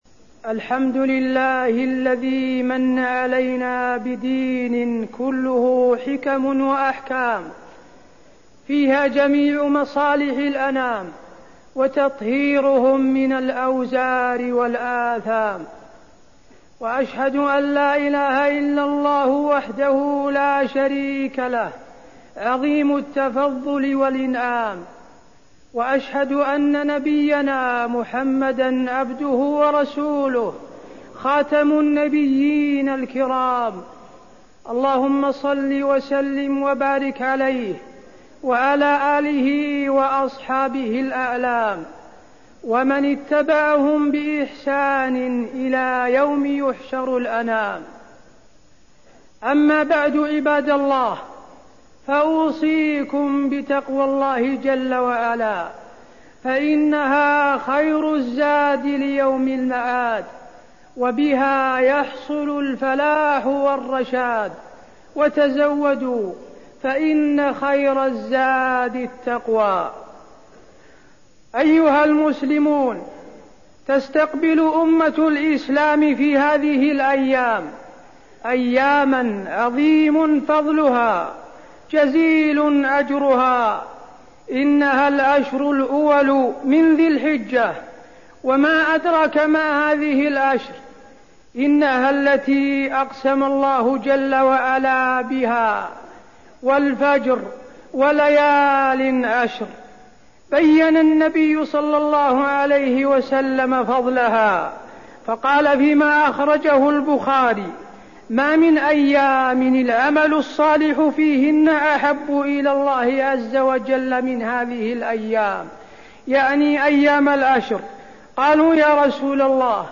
تاريخ النشر ٢٩ ذو القعدة ١٤١٨ هـ المكان: المسجد النبوي الشيخ: فضيلة الشيخ د. حسين بن عبدالعزيز آل الشيخ فضيلة الشيخ د. حسين بن عبدالعزيز آل الشيخ فضل عشر من ذي الحجة